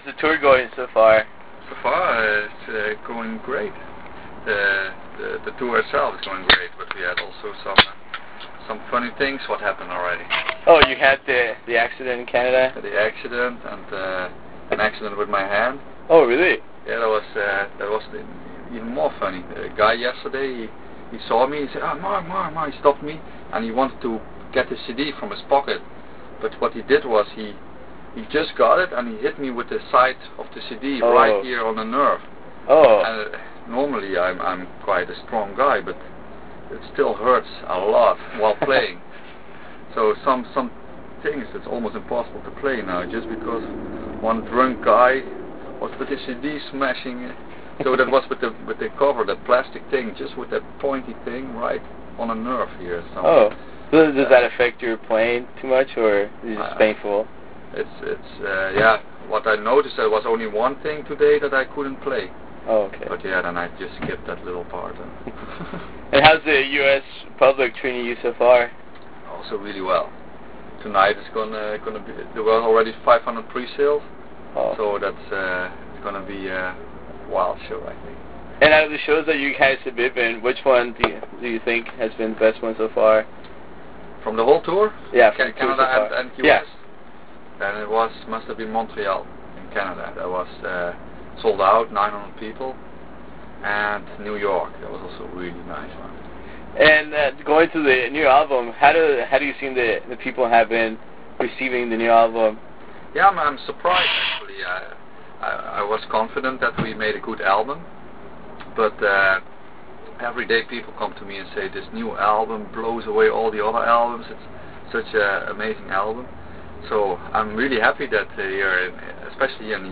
Interview with Epica - Mark Jansen (Design Your Universe NA Tour 2010)
Before their amazing show at El Corazon in Seattle, WA.
Interview with Mark Jansen - Epica (Design Your Universe tour 2010).wav